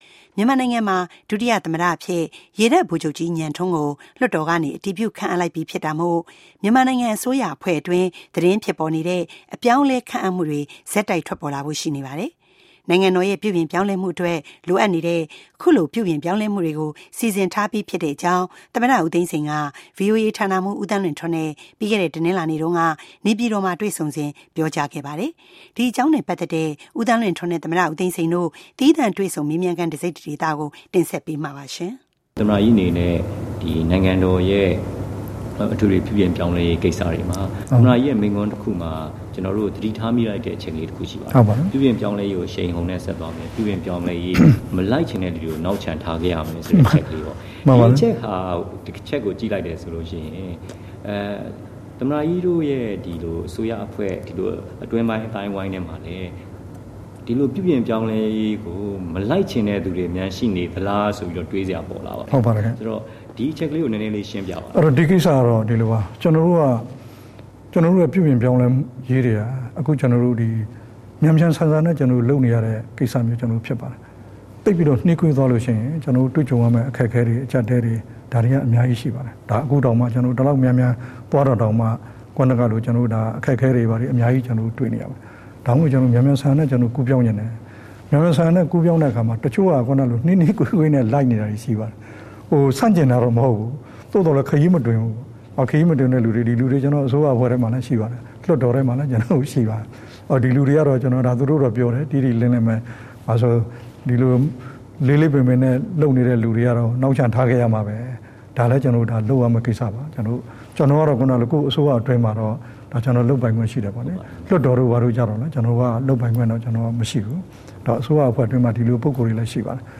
သမ္မတနဲ့အင်တာဗျူး